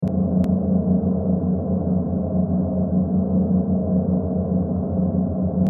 جلوه های صوتی
دانلود صدای قایق 8 از ساعد نیوز با لینک مستقیم و کیفیت بالا